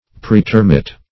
Praetermit \Pr[ae]`ter*mit"\, v. t.